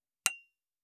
288食器をぶつける,ガラスをあてる,皿が当たる音,皿の音,台所音,皿を重ねる,カチャ,
コップ効果音厨房/台所/レストラン/kitchen食器